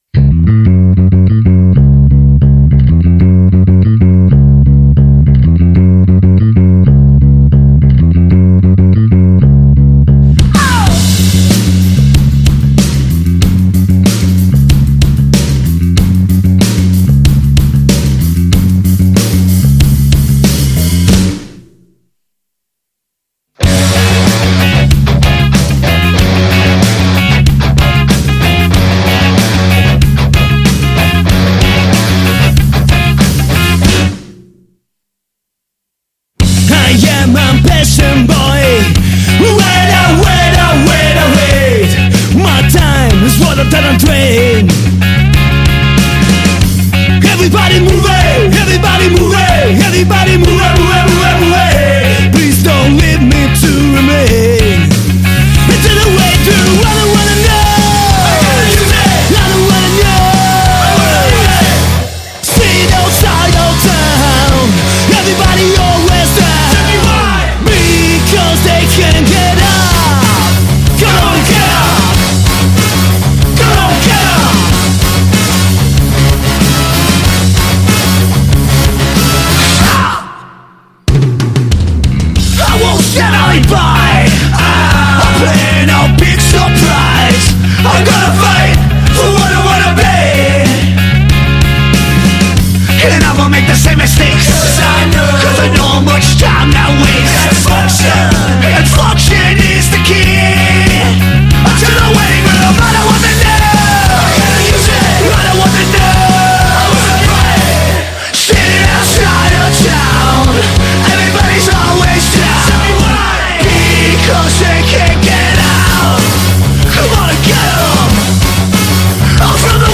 Intervista agli NH3 | 16-1-23 |Punk on the Rocks | Radio Città Aperta